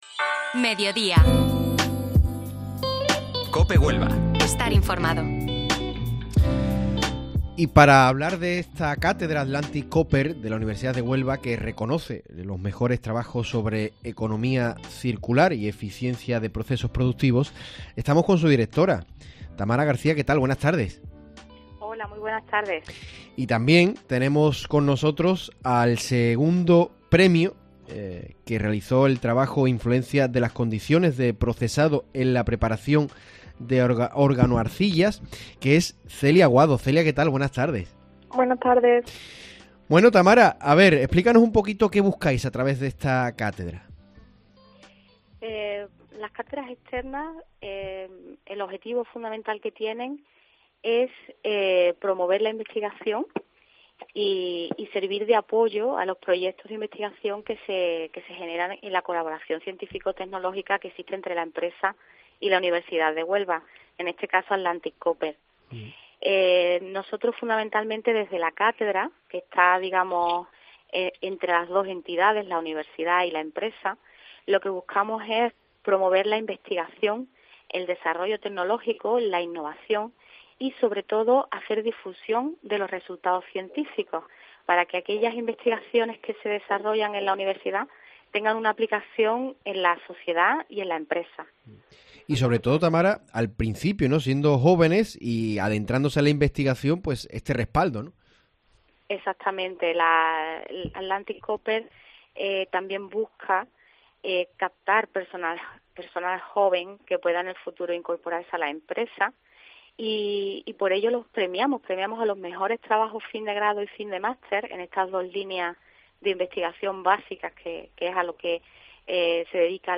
Ambas ponen en valor esta cátedra que busca "la innovación en economía circular y excelencia ambiental, y la eficiencia de procesos productivos", además de perseguir el "objetivo de la promoción de la investigación aplicada al campo de la metalurgia del cobre y materias tecnológicas relacionadas".